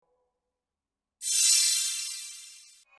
Звуки вспышки
Осветило